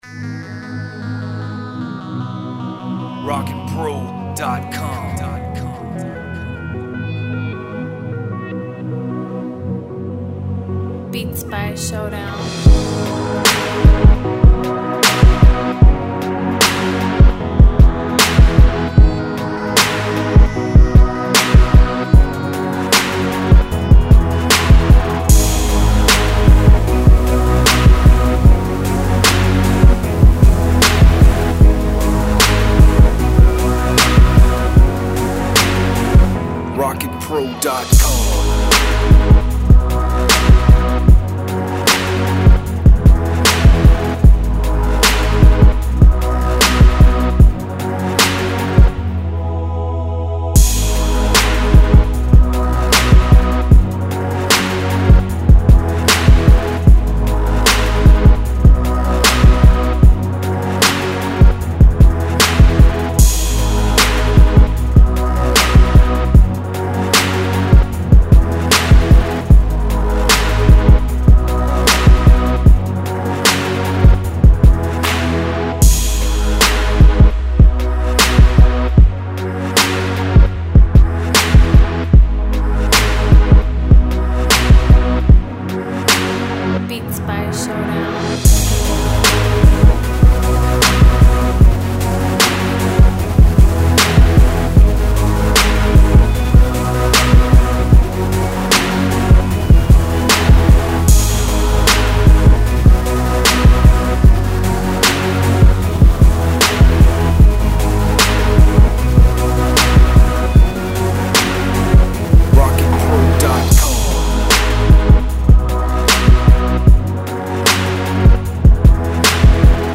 161 BPM.